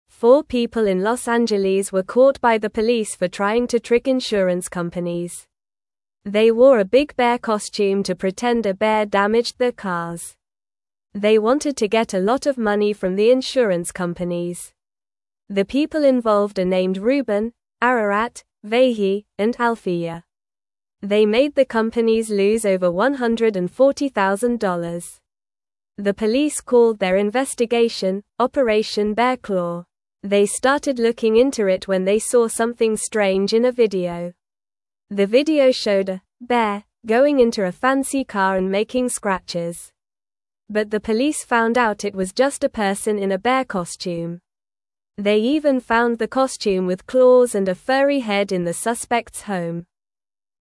Normal
English-Newsroom-Beginner-NORMAL-Reading-People-Pretend-Bear-Damaged-Cars-for-Money.mp3